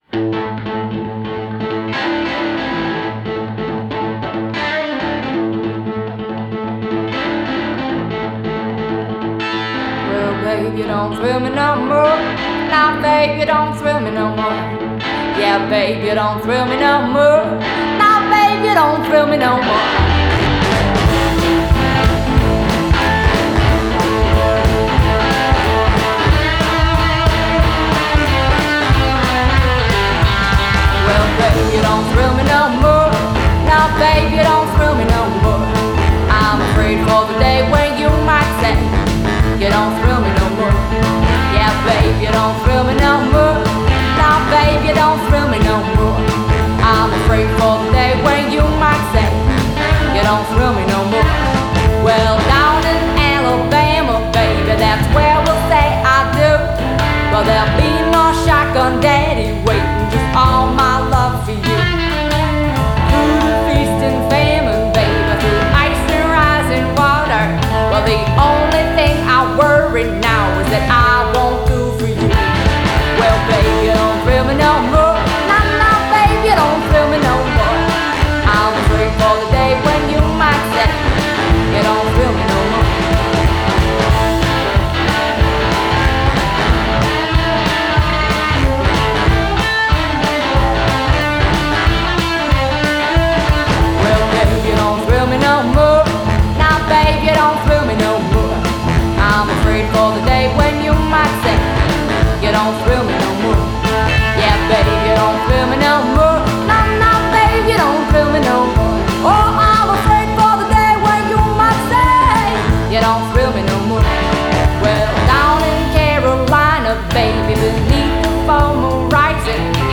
From cowboy songs to Bluegrass, Harlem Jazz to Honky-Tonk